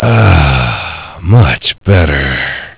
Worms speechbanks
brilliant.wav